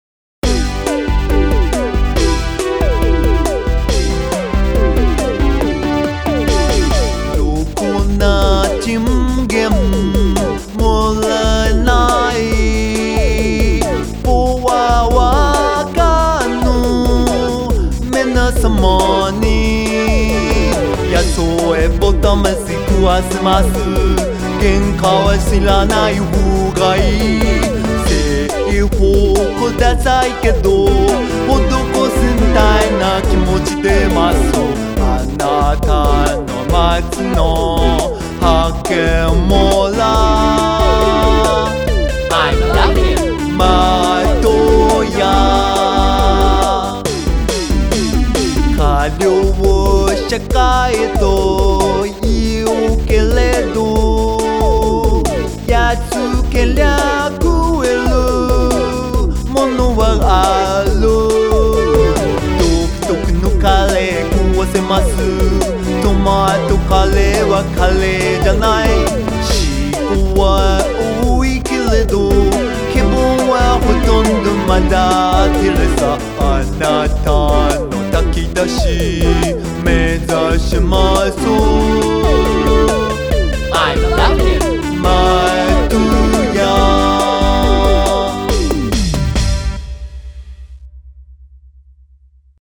大衆に訴えかけるポップなアレンジを、経費削減のため、歌手を雇わずに隣国からのバイト店員に歌ってもらったところ、初々しさが極まって素敵なクオリティに……。